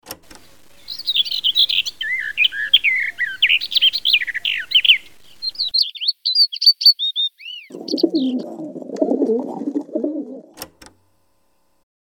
micro-trottoir printanier
GJstudio_MicroTrottoir_Parisien.mp3